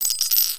1 channel
GetCoin.mp3